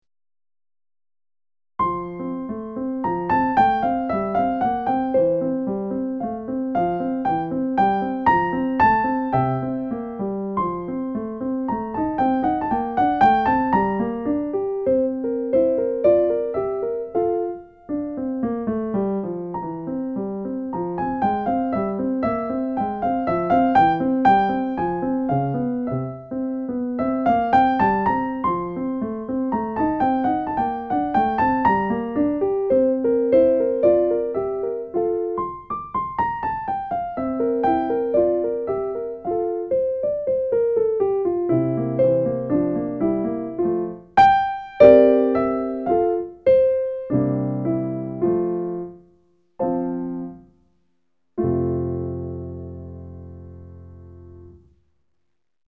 Performance c.104bpm